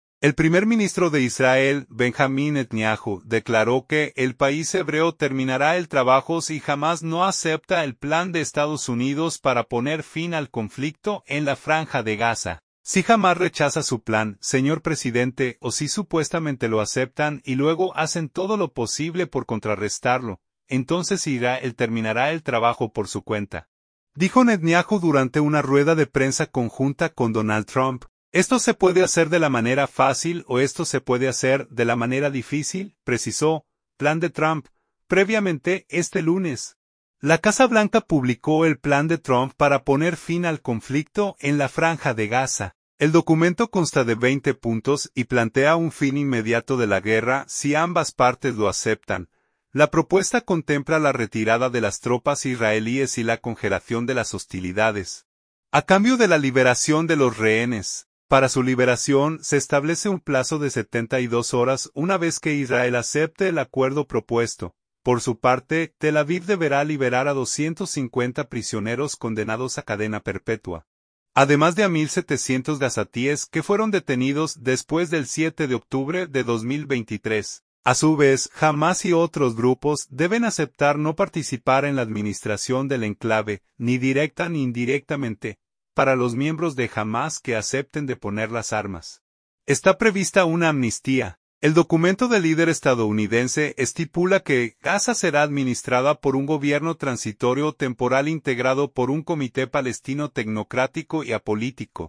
"Si Hamás rechaza su plan, señor presidente, o si supuestamente lo aceptan y luego hacen todo lo posible por contrarrestarlo, entonces Israel terminará el trabajo por su cuenta", dijo Netanyahu durante una rueda de prensa conjunta con Donald Trump.